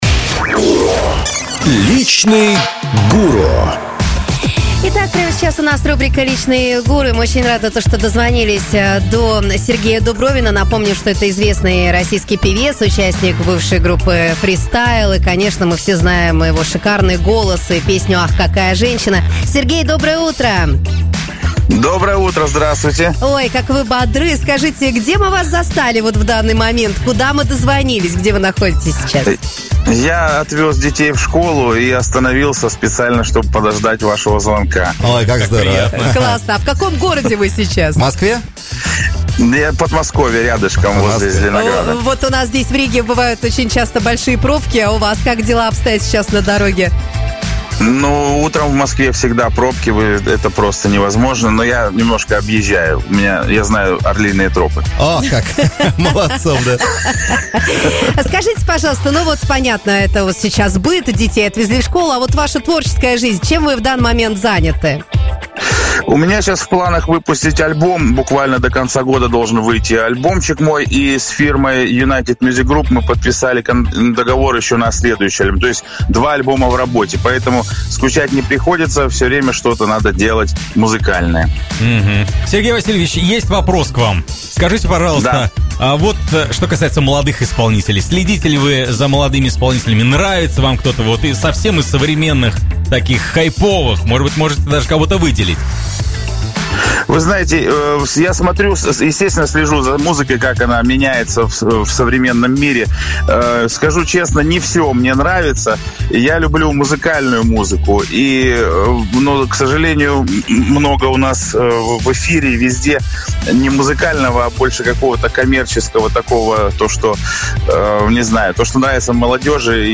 Об этом и многом другом – в интервью Сергея Дубровина «Авторадио».